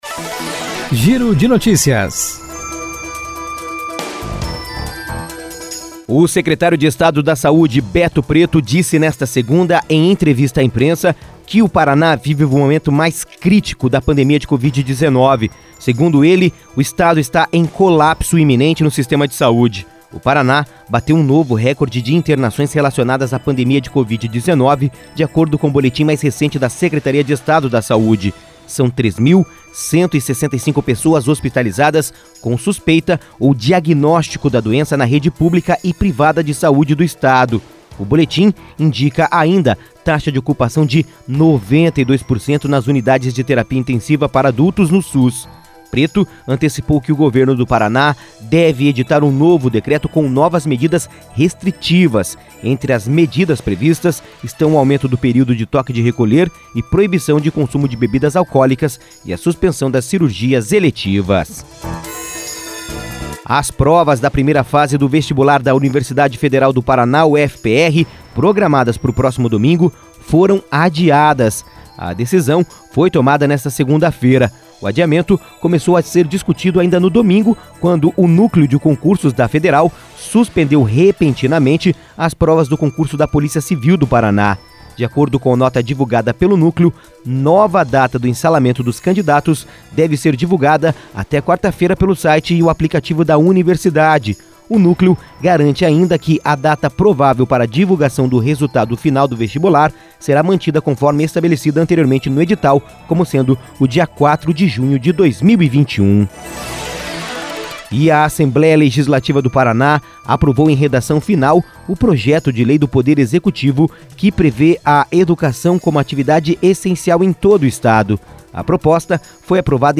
Giro de Notícias (COM TRILHA)